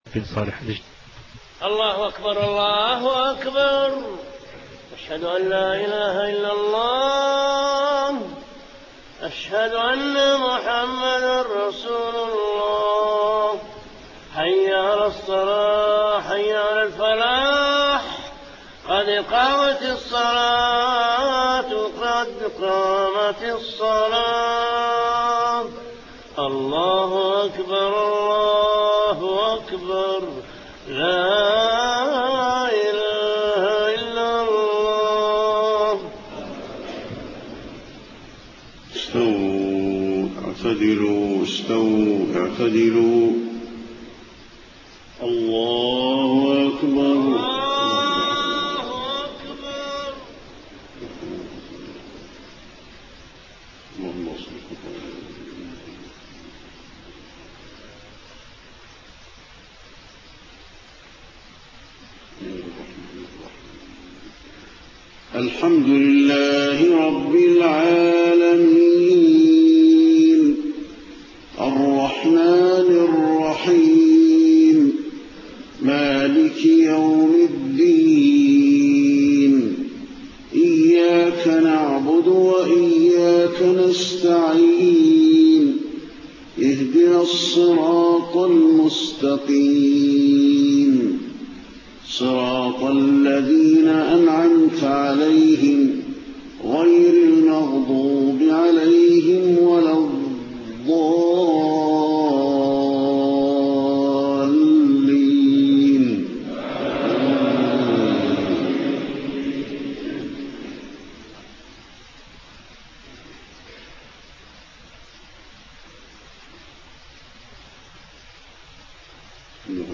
صلاة المغرب 27 محرم 1431هـ سورتي التين و القارعة > 1431 🕌 > الفروض - تلاوات الحرمين